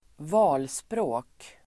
Ladda ner uttalet
valspråk substantiv, motto Uttal: [²v'a:lsprå:k] Böjningar: valspråket, valspråk, valspråken Synonymer: devis, motto, paroll Definition: kort uttryck som sammanfattar syftet med en (persons el. grupps) verksamhet (slogan)